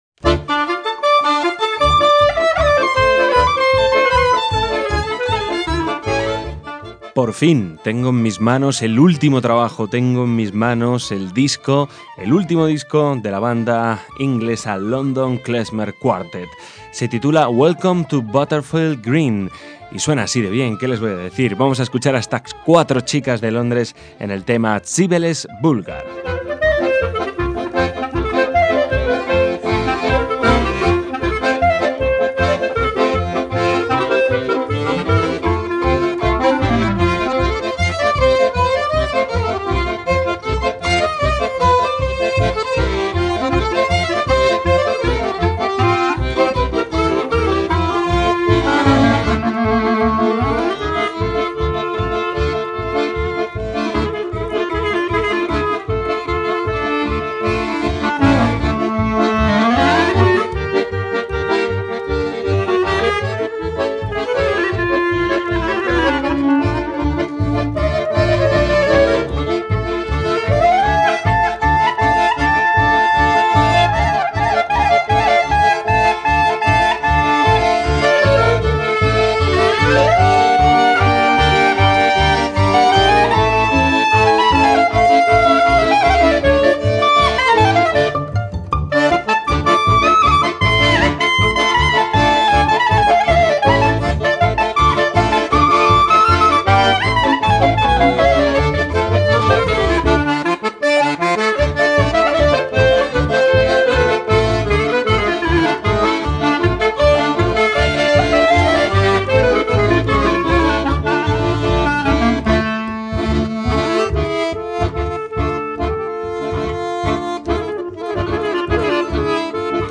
violín
clarinete
acordeón
chelo o contrabajo